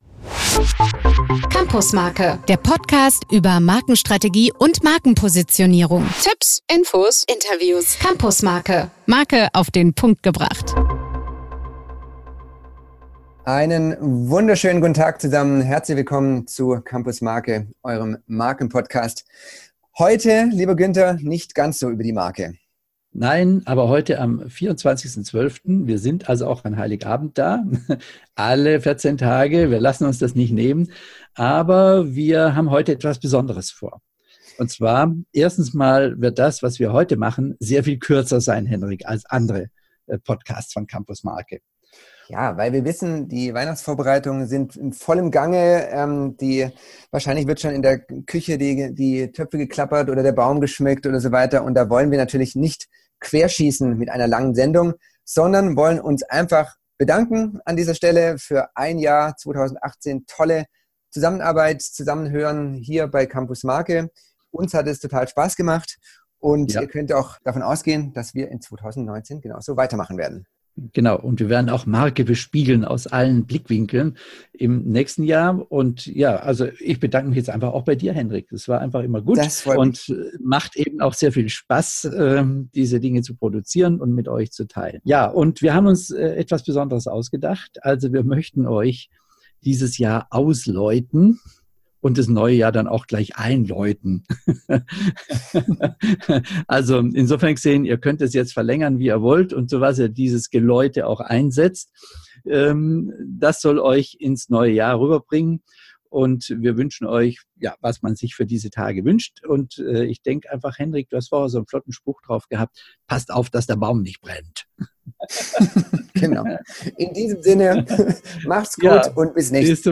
Weihnachtliches Glockengeläut - Stephansdom Wien, YouTube